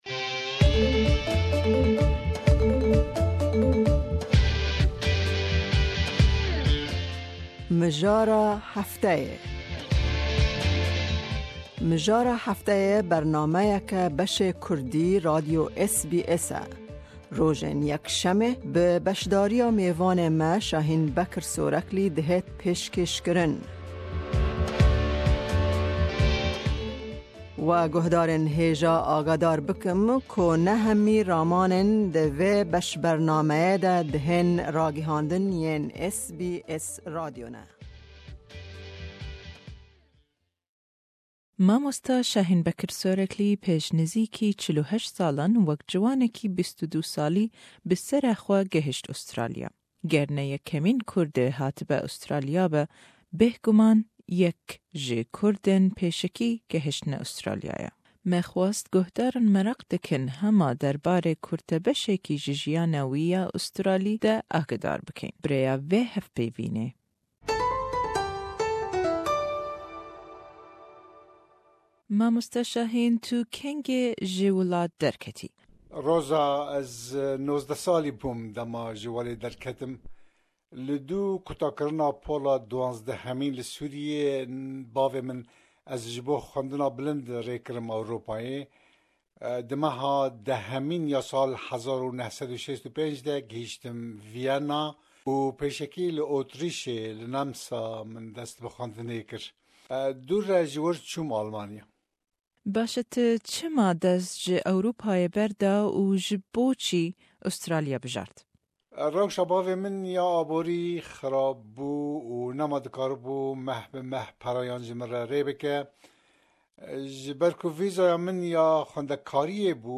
Me xwazt em guhdarên meraq dikin hema derbarê kurtebeshekî ji jiyana wî ya australî de agahdar bikin, bi rêya vê hevpevînê.